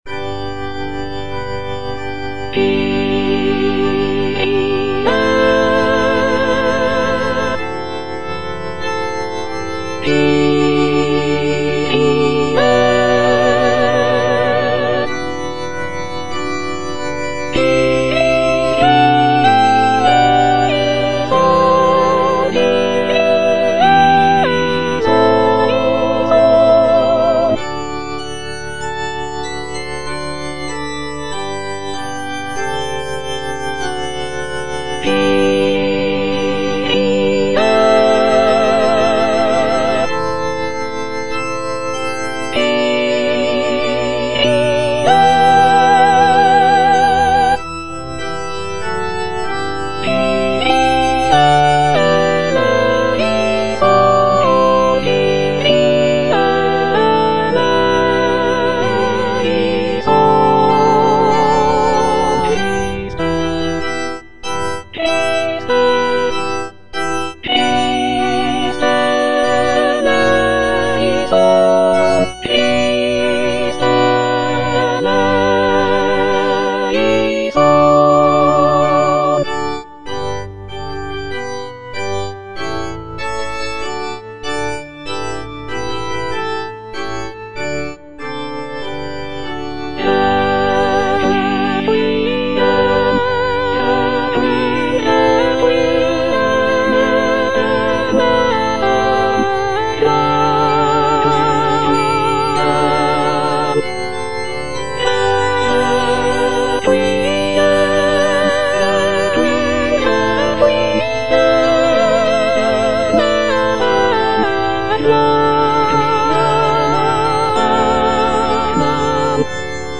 Soprano (Emphasised voice and other voices) Ads stop
is a sacred choral work rooted in his Christian faith.